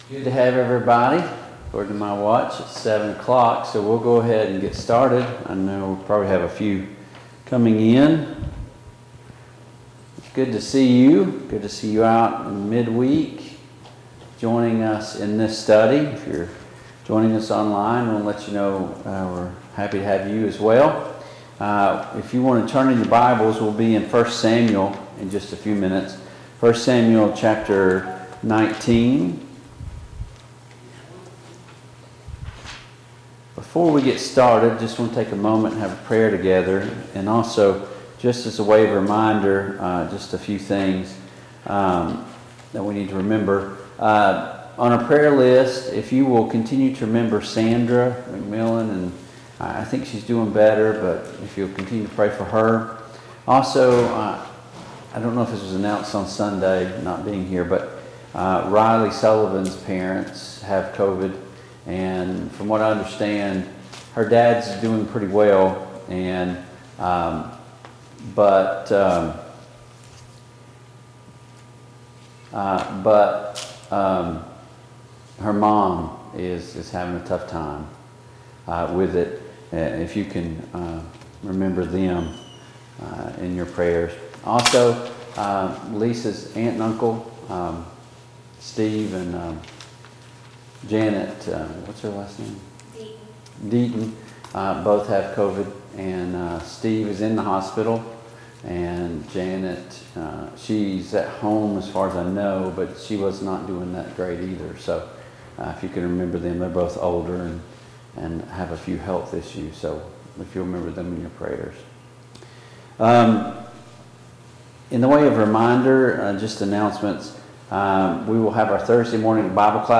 The Kings of Israel Passage: I Samuel 16-19 Service Type: Mid-Week Bible Study Download Files Notes « The Ideal Audience 8.